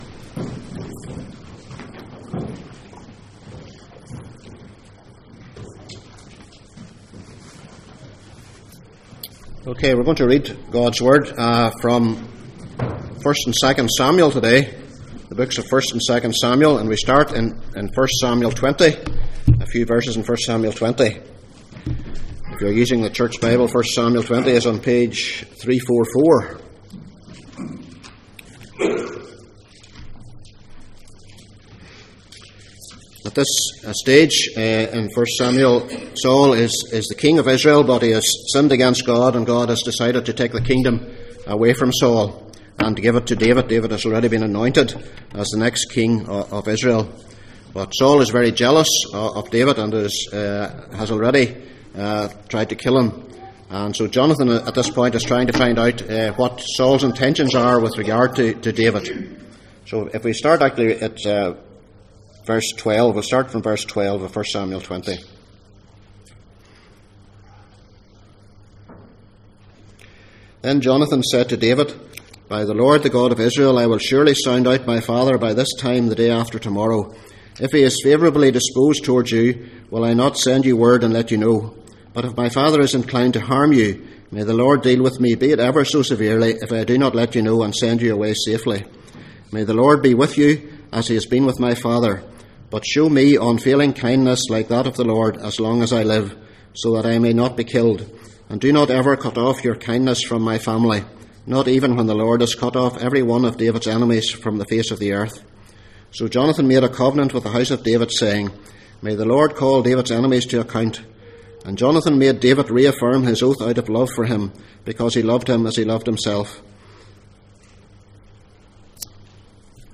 Passage: 1 Samuel 20:12-16, 2 Samuel 4:4, 2 Samuel 9:1-13 Service Type: Sunday Morning %todo_render% « Jesus Claims